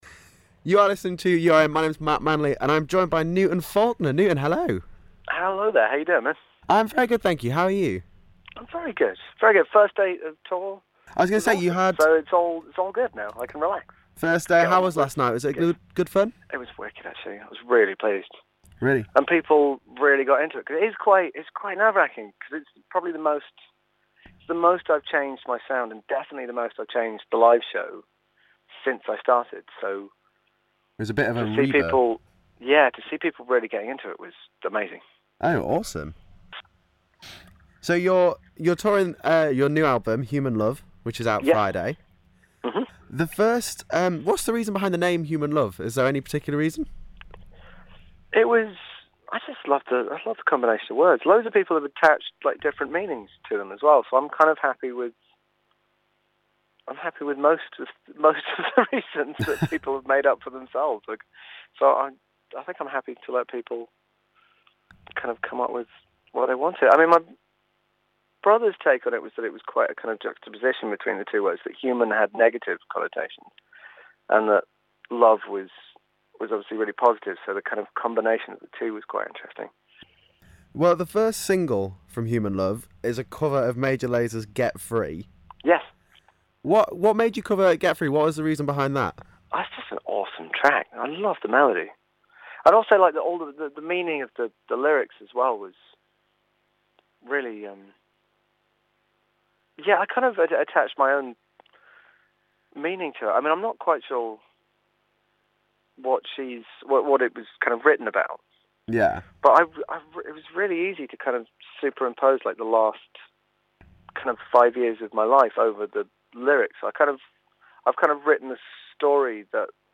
Newton Faulkner Interview